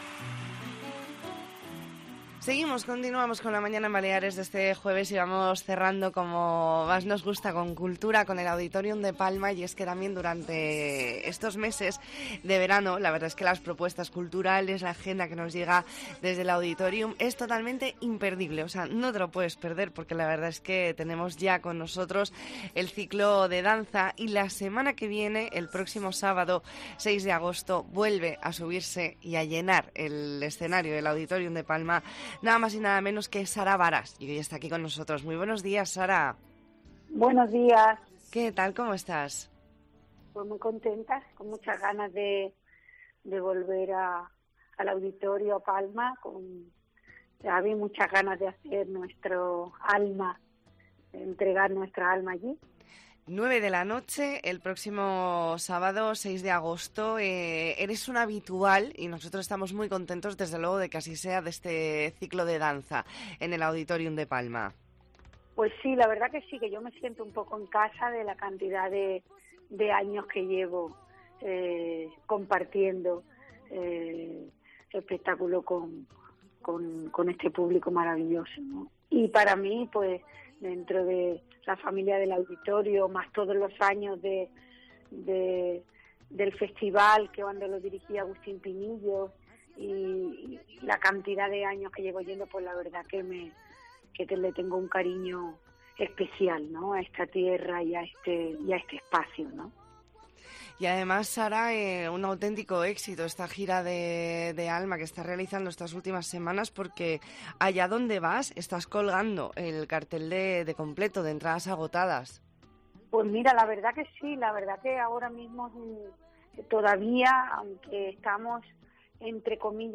Redacción digital Madrid - Publicado el 28 jul 2022, 13:50 - Actualizado 18 mar 2023, 18:35 1 min lectura Descargar Facebook Twitter Whatsapp Telegram Enviar por email Copiar enlace Hablamos con Sara Baras.